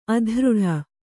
♪ adřḍha